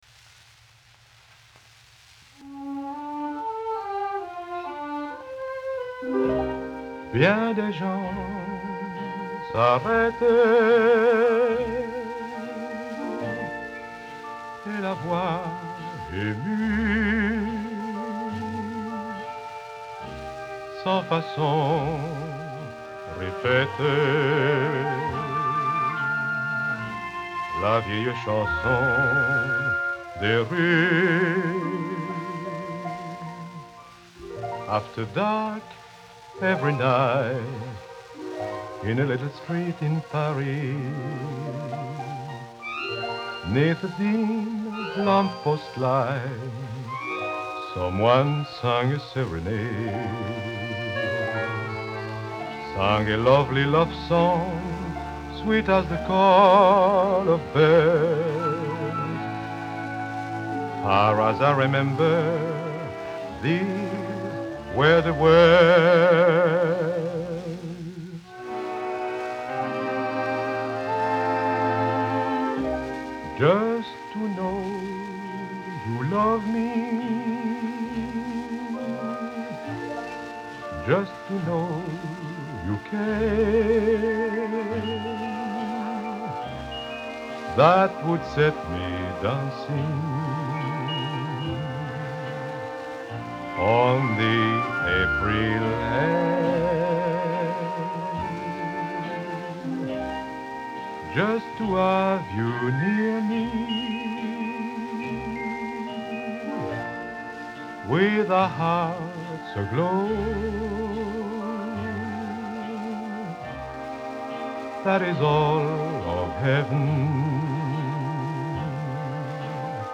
очень романтический стиль.